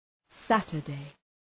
Προφορά
{‘sætərdı}